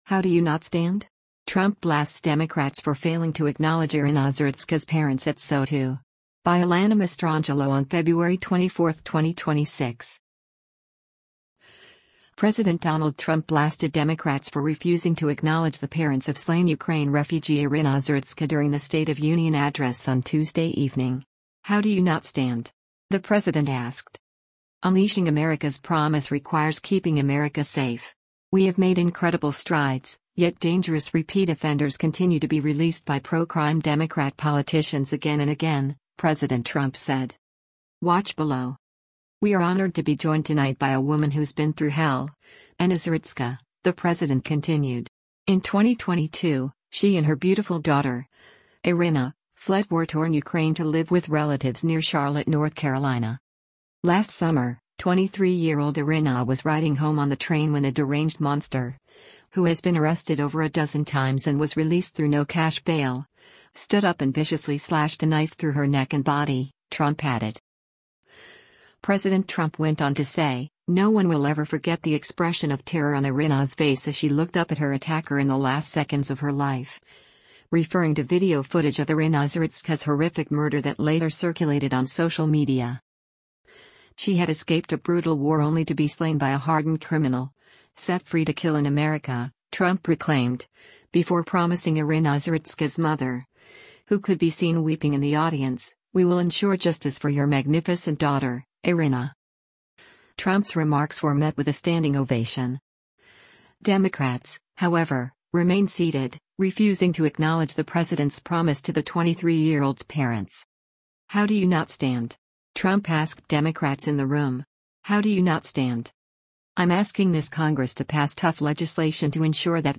“Unleashing America’s promise requires keeping America safe. We have made incredible strides, yet dangerous repeat offenders continue to be released by pro-crime Democrat politicians again and again,” President Trump said.
Trump’s remarks were met with a standing ovation.
“How do you not stand?” Trump asked Democrats in the room. “How do you not stand?”